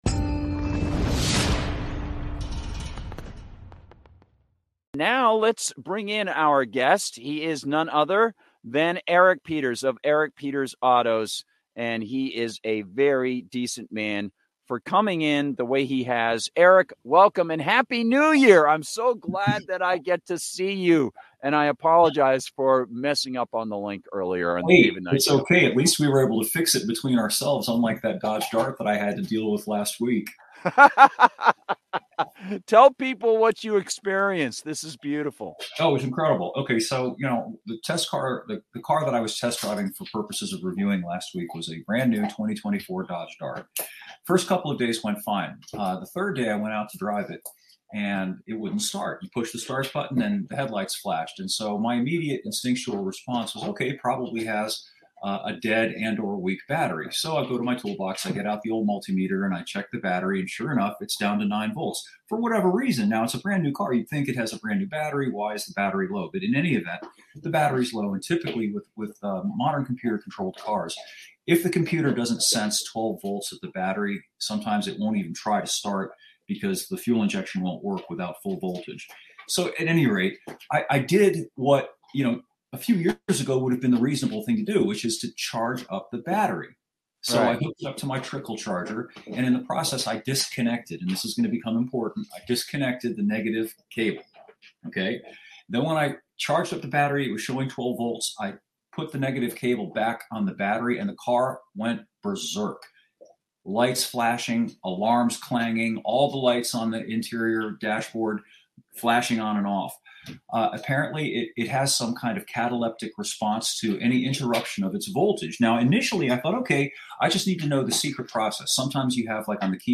interview-your-car-as-government-surveillance.mp3